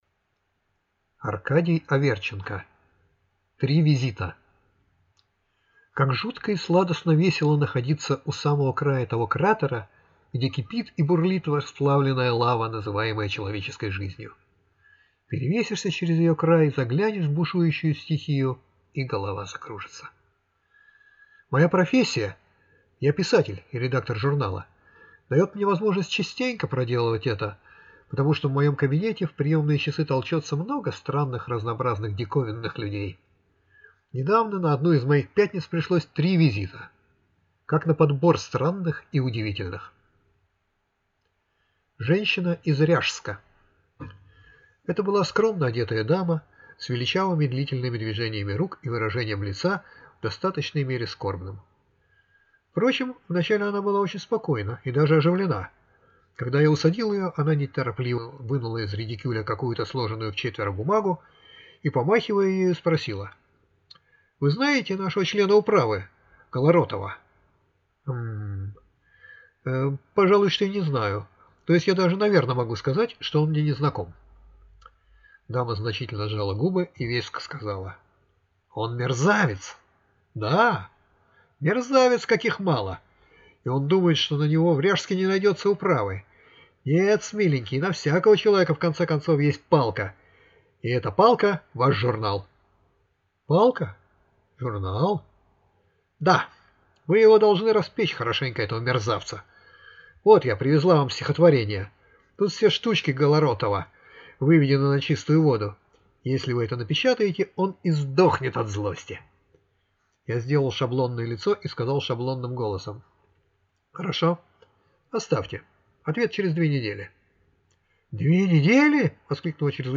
Аудиокнига Три визита | Библиотека аудиокниг